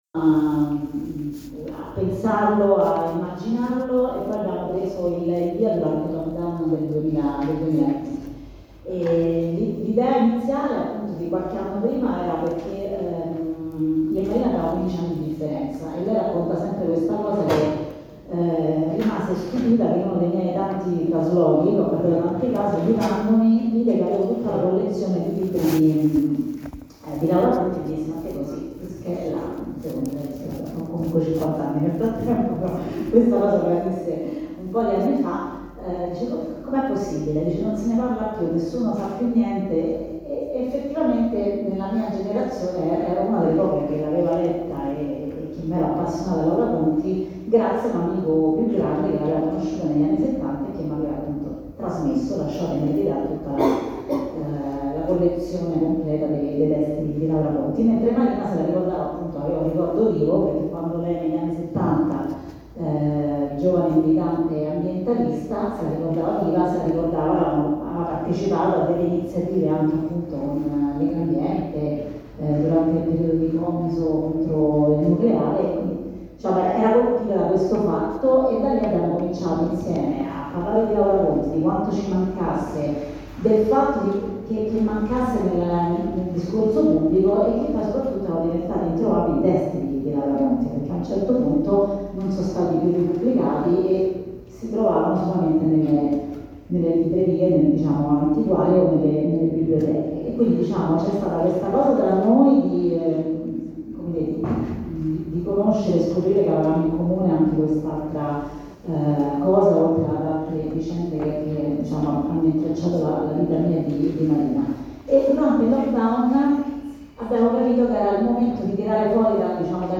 il secondo appuntamento della rassegna Al limite, un libro, inserita nella stagione culturale di Villa del Grumello e dedicata a tematiche socio-ambientali, era dedicato alla figura di Laura Conti, tanto importante quanto trascurata.